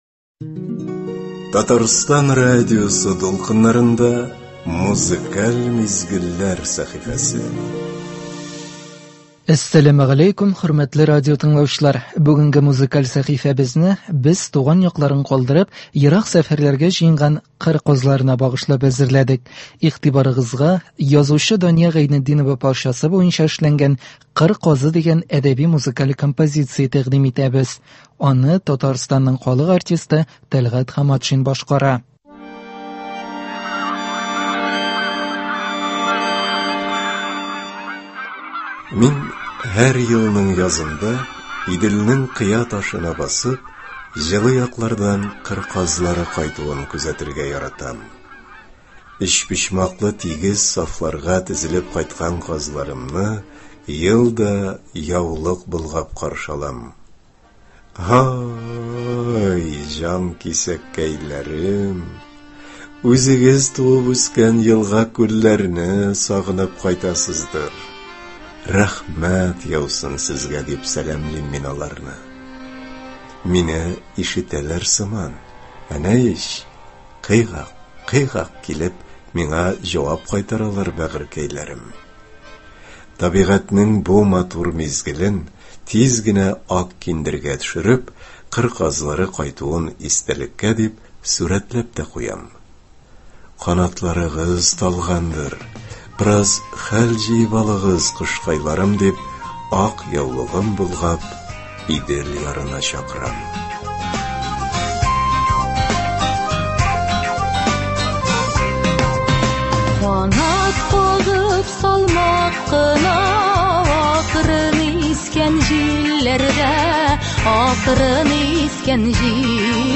Бүгенге музыкаль сәхифәбезне без туган якларын калдырып, ерак сәфәрләргә җыенган кыр казларына багышлап әзерләдек. Игътибарыгызга язучы Дания Гайнетдинова парчасы буенча эшләнгән “Кыр казы” дигән әдәби-музыкаль композиция тәкъдим итәбез.